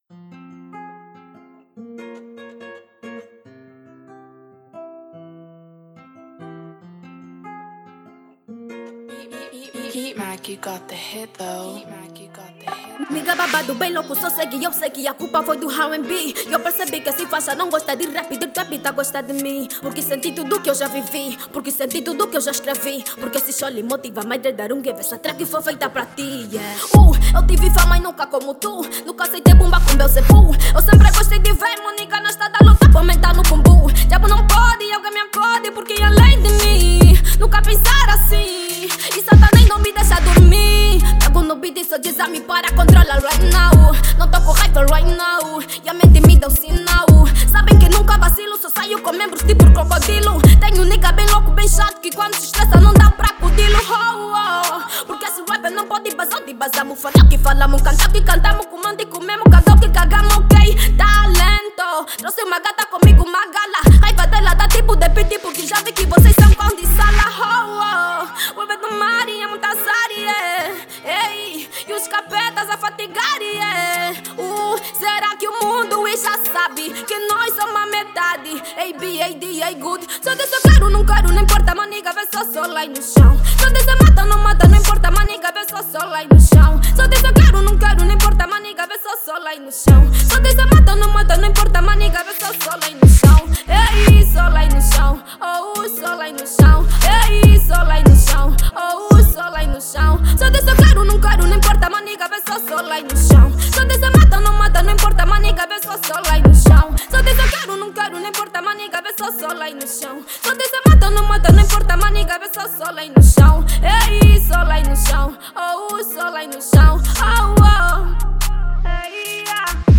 Drill
uma música do gênero drill